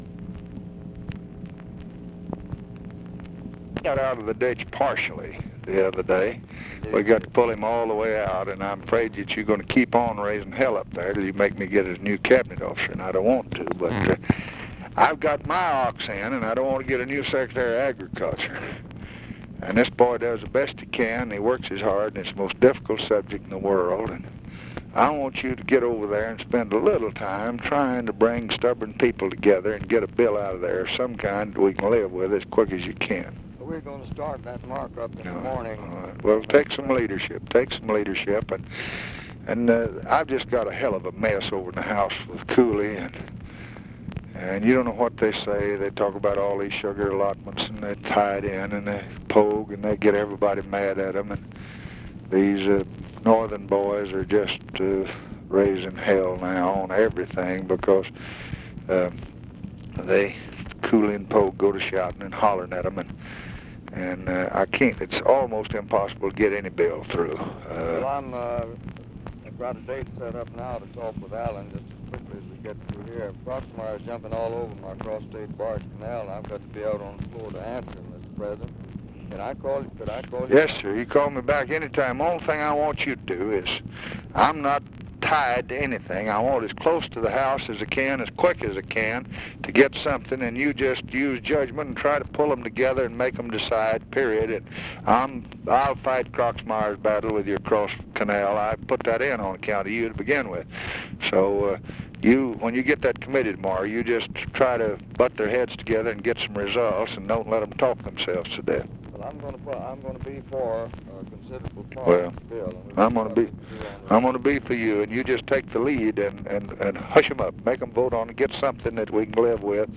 Conversation with SPESSARD HOLLAND, August 23, 1965
Secret White House Tapes